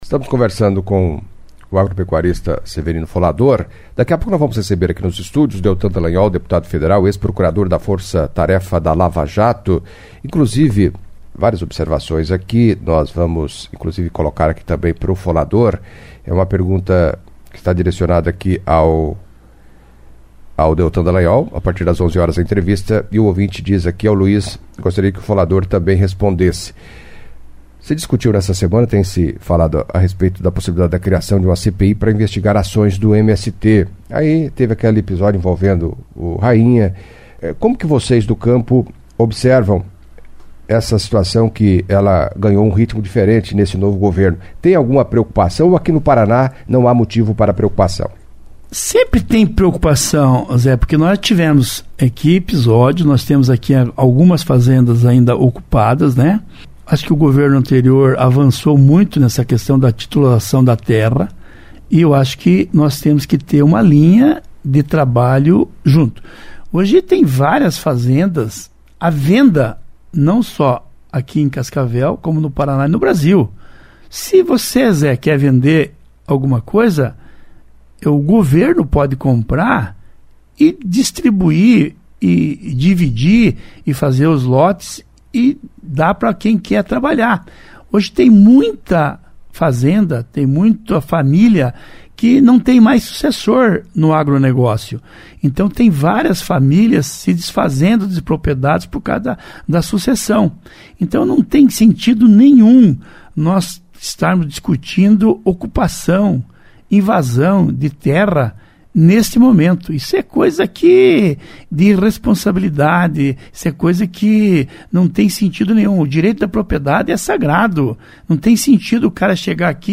Em entrevista à CBN Cascavel nesta sexta-feira (10)